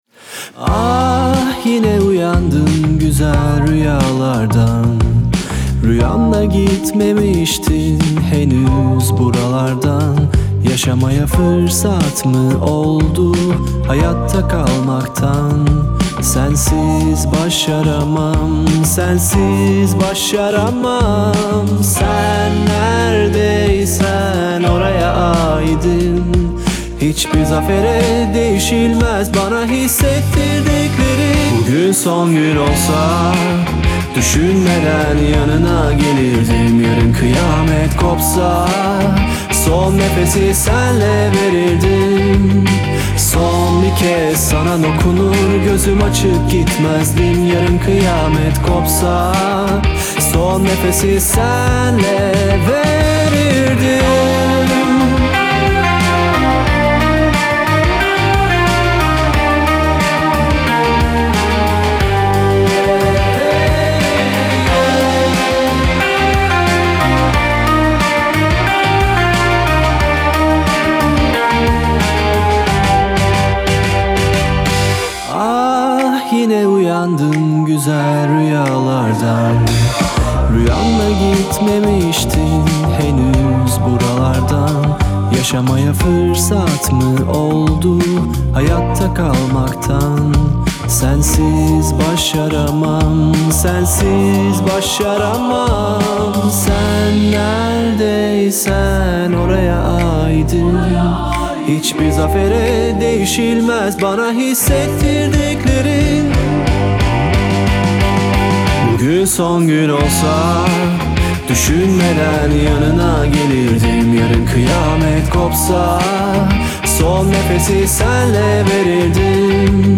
Трек размещён в разделе Турецкая музыка / Рок.